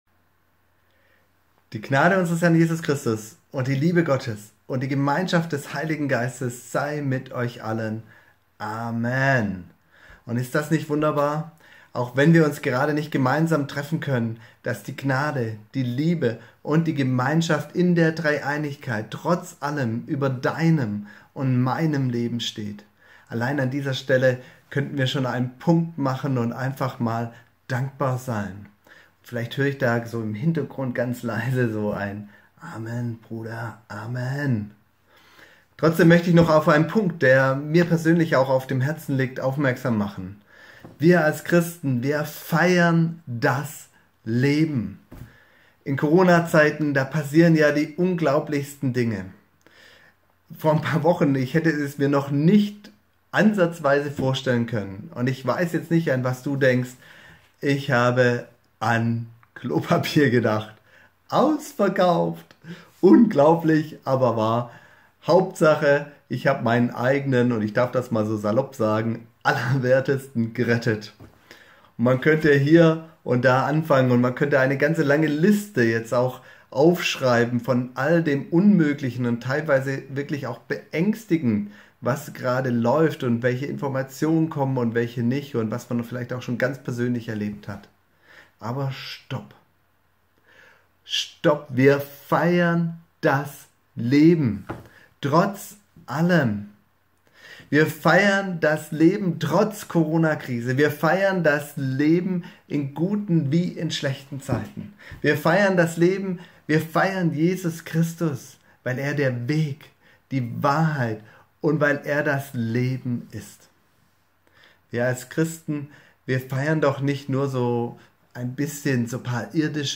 Das Leben feiern || Impuls 26.03.2020